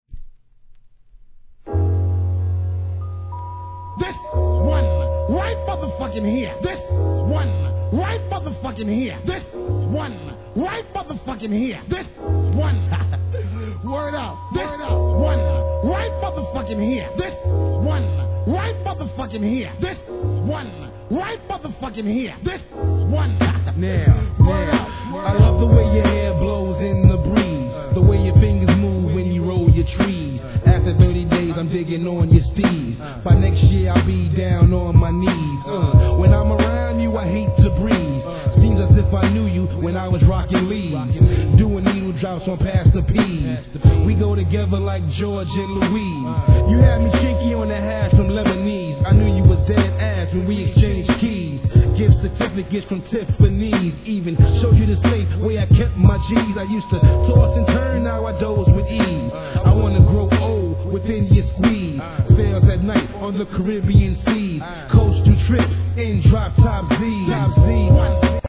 HIP HOP/R&B
お洒落なメロ〜トラックに女性コーラス・フックのA面に